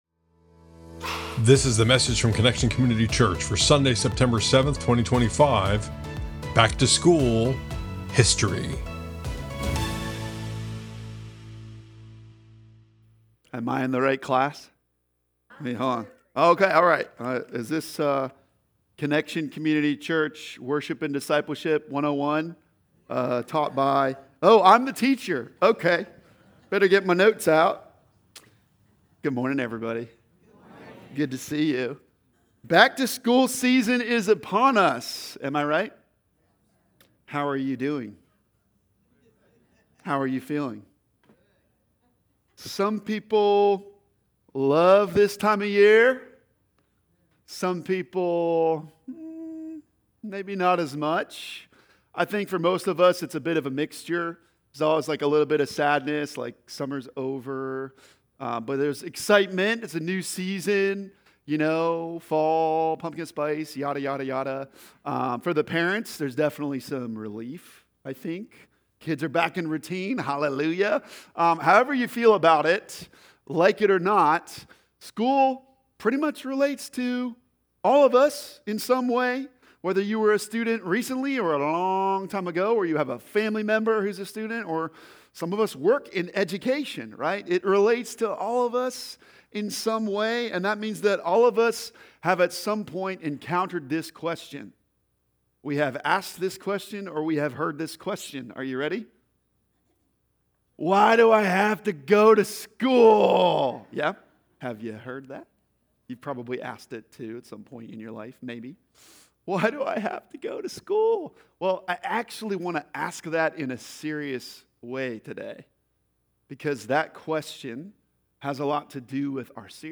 Methodist # Middletown Delaware # Dover Delaware # Connection Community Church # Christianity # Sermons